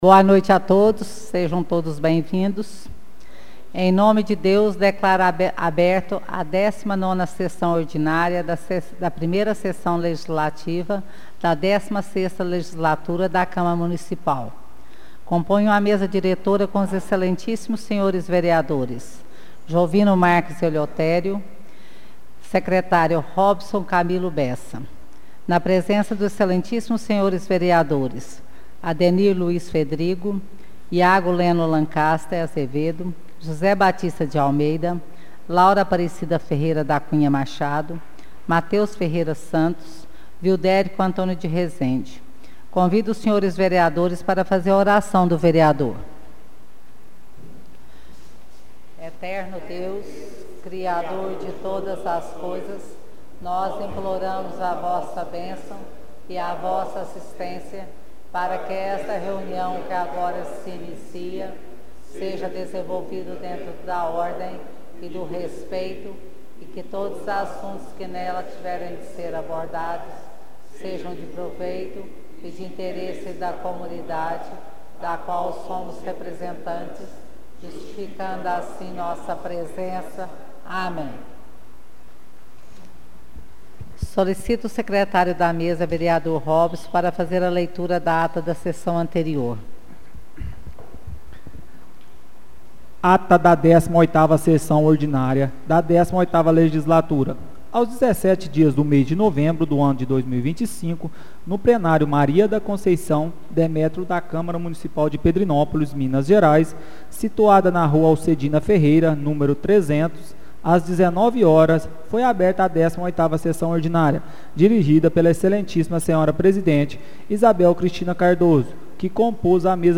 Áudio da 19ª Sessão Ordinária de 2025